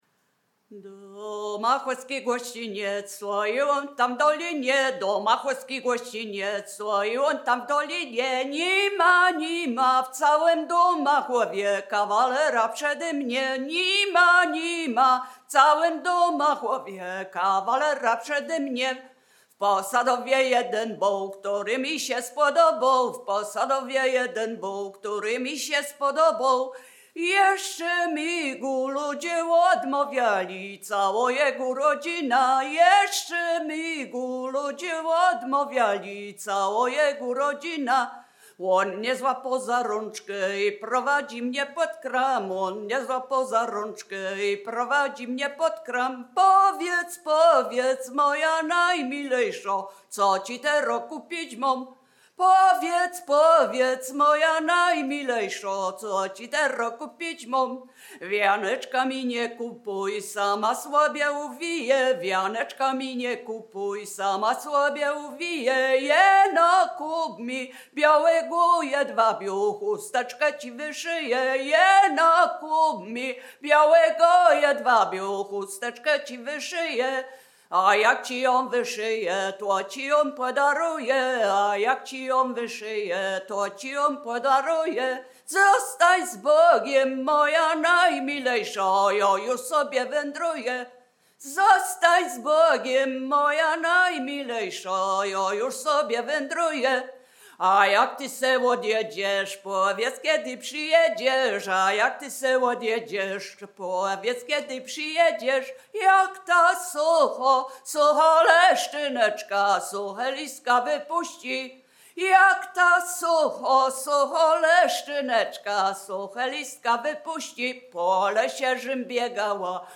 Wielkopolska
Obyczajowa